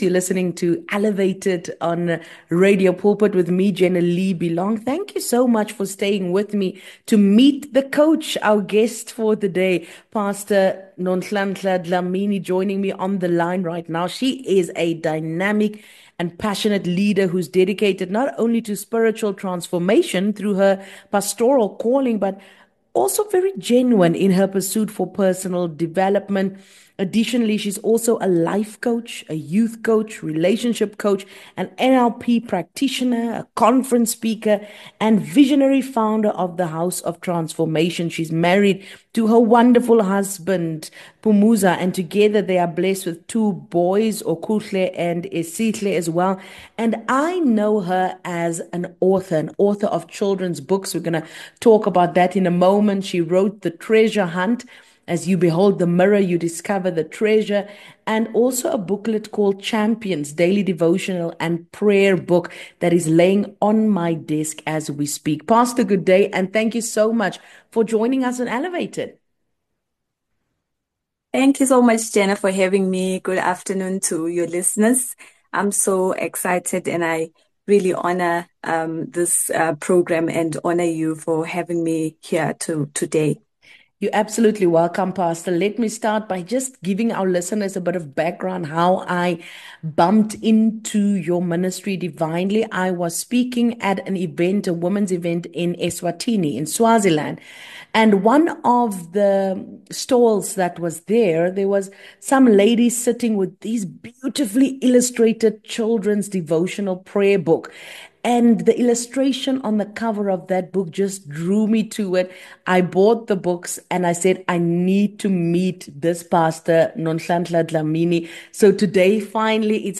It will uplift your spirit and empower you through the Word of God. The highlight of the show is the powerful testimonies shared by guests.